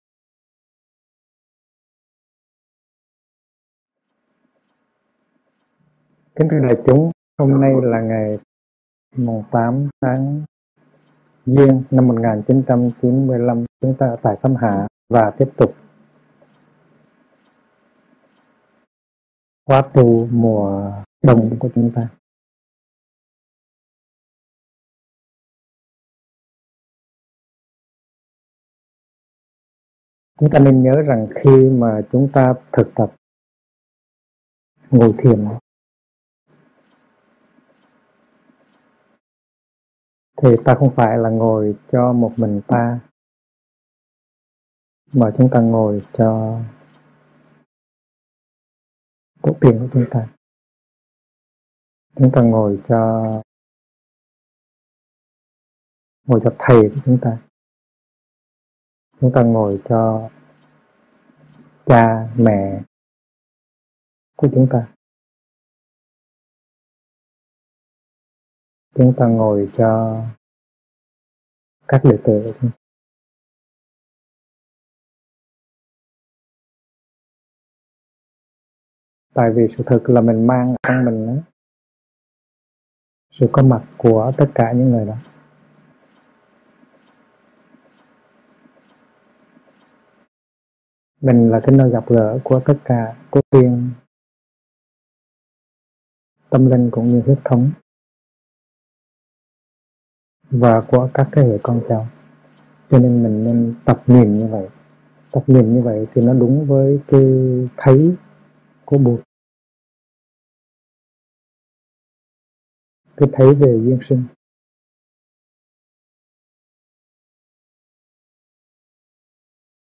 Thuyết pháp Phương pháp đạt thiền - HT. Thích Nhất Hạnh
Mời quý phật tử nghe mp3 thuyết pháp Phương pháp đạt thiền do HT. Thích Nhất Hạnh giảng